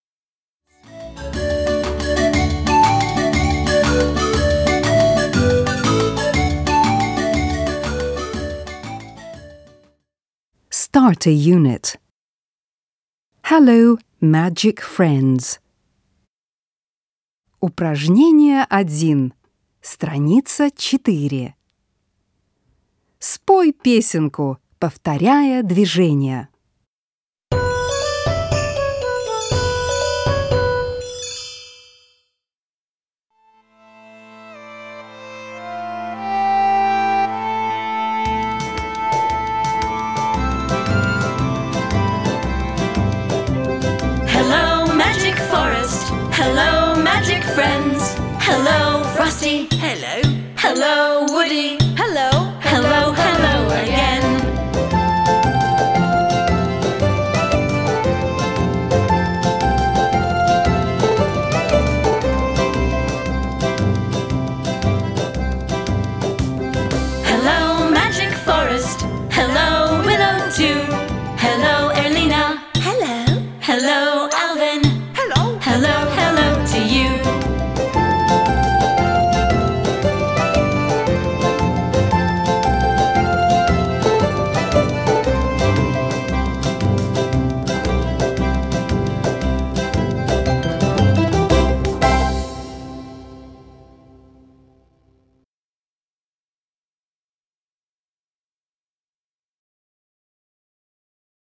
1. Спой песенку, повторяя движения.